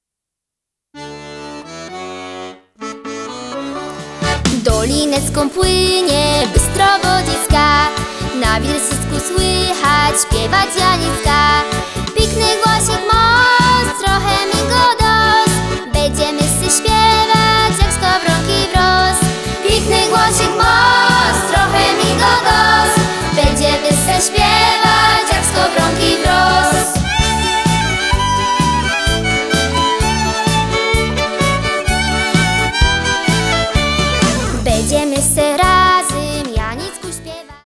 Podkład muzyczny akordeonowy - studyjny.